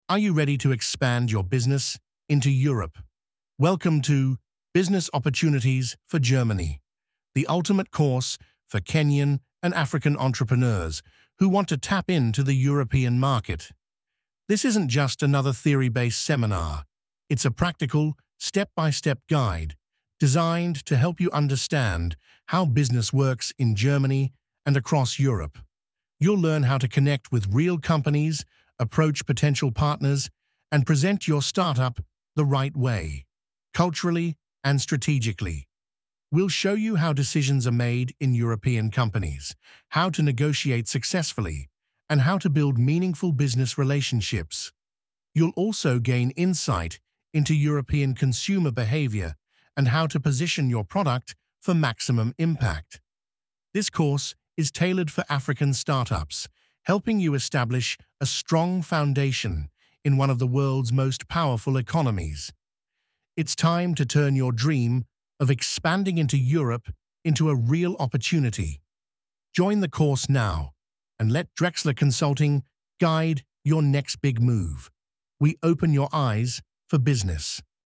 Audio Guide: Discover how Kenyan entrepreneurs can enter the German market successfully.